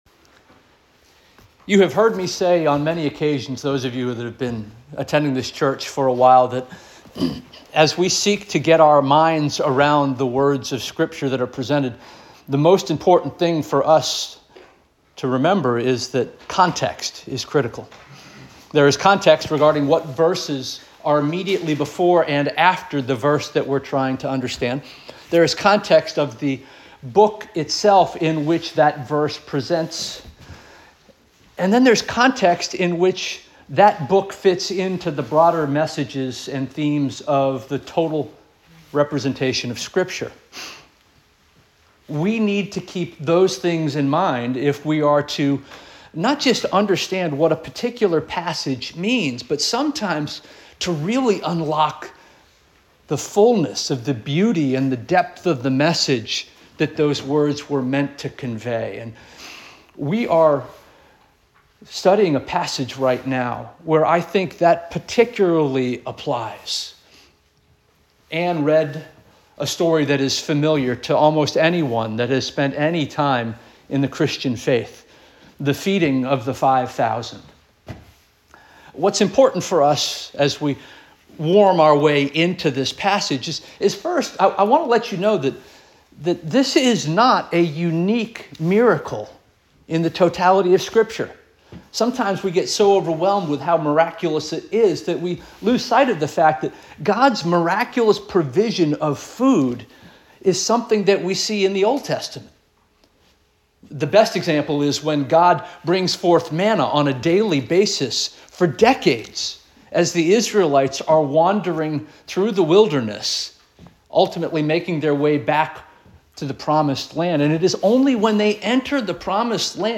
December 7 2025 Sermon - First Union African Baptist Church